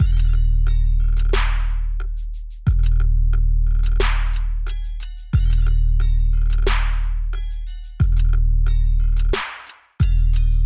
西海岸 鼓声循环
描述：很好的循环（循环：镲片，小鼓，踢腿，次低音，帽子，振动器和Rimshot）。
Tag: 90 bpm Hip Hop Loops Drum Loops 1.79 MB wav Key : Unknown